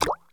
Potion Drink (2).wav